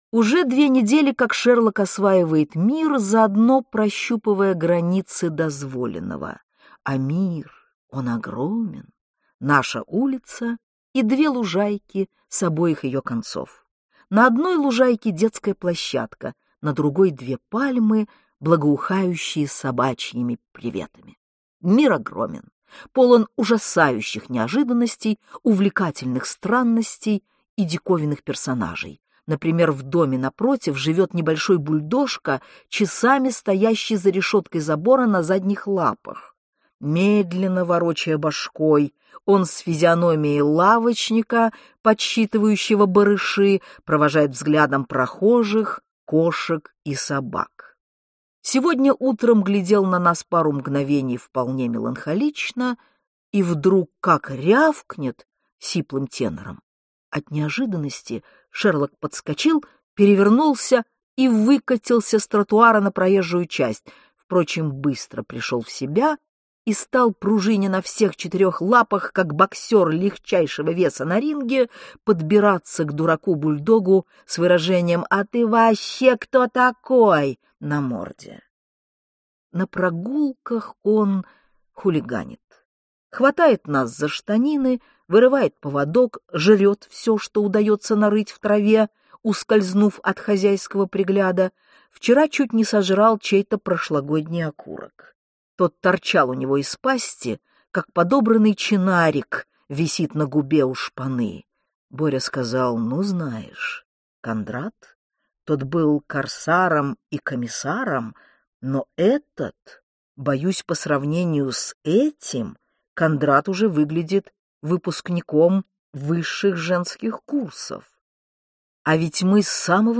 Aудиокнига Любовь – штука деликатная Автор Дина Рубина Читает аудиокнигу Дина Рубина.